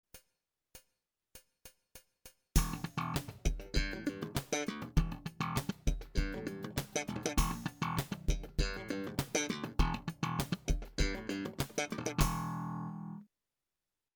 Jazzitalia - Lezioni: Basso Elettrico
Esercizi per lo slap
L'esercizio consiste nell'utilizzare per lo slap l'indice che strappa la corda Re e il medio per la corda Sol, mentre il pollice percuote le corde La e Mi.
Si riesce così ad ottenere un "groove" molto "pieno" e soprattutto con moltissime dead notes, inoltre permette di alternare sempre i movimenti evitando così di percuotere 2 volte nello stesso modo o di avere solo 2 o 3 percussioni alternate, (si può arrivare a 5 e oltre es: T, H, T, P(i), P(m) …..) aumentando quindi la velocità di esecuzione senza irrigidire la mano destra.